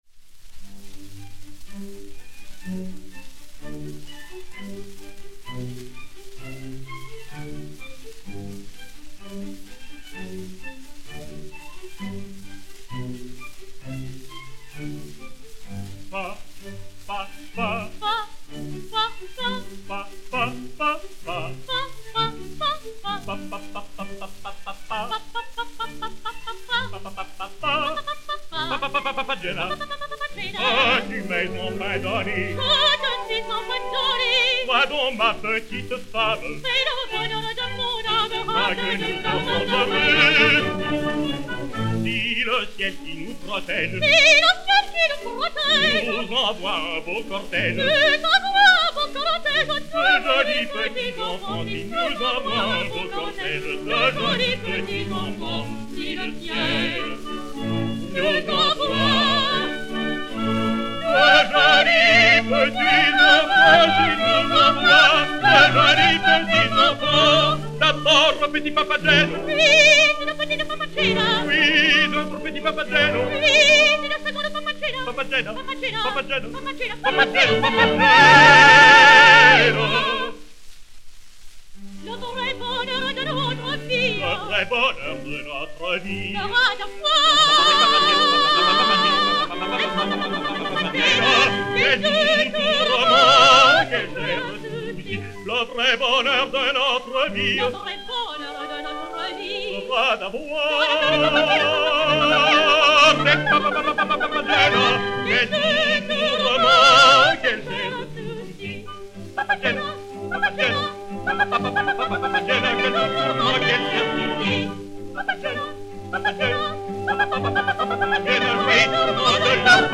LX 808, enr. à l'Opéra de Paris le 15 janvier 1929